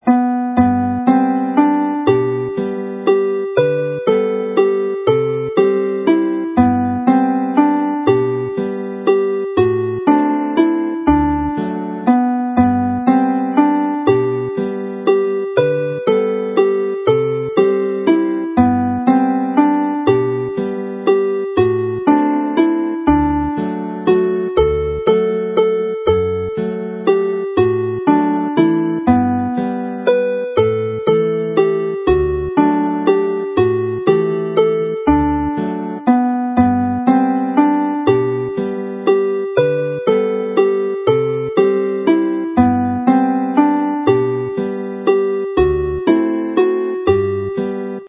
A simplified version of the Plygain carol Hir Oes i Fair (a long life to Mary) follows in G major.